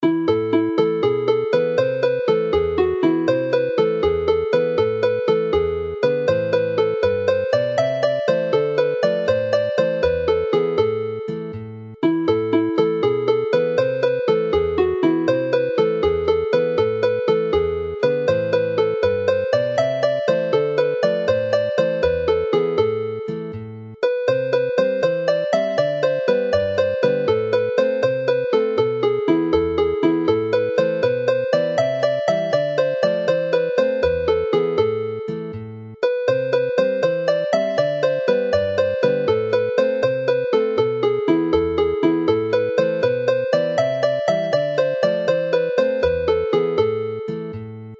Hyder as a Jig
Jig Hyder is a dance tune based on this melody